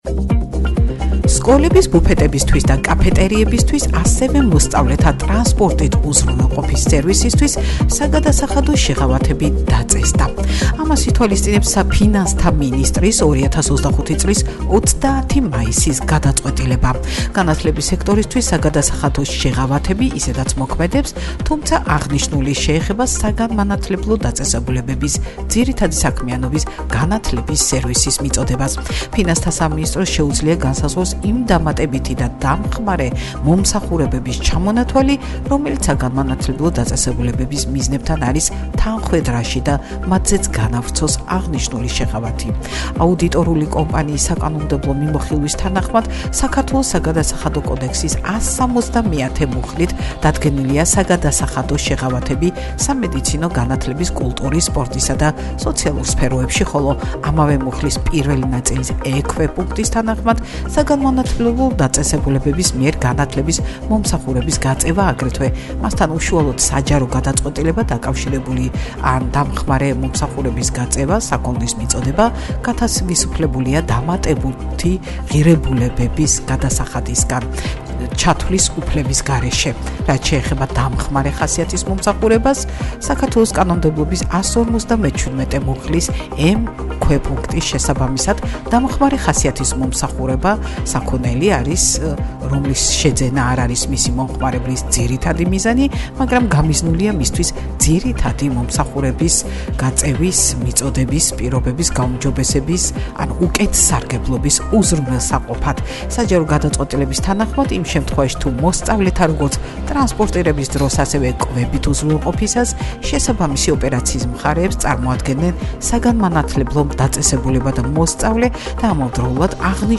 Georgian Fashion Week თბილისში: მოდა, ბიზნესი და საერთაშორისო მასშტაბები – ინტერვიუ “ბიზნესრევიუში“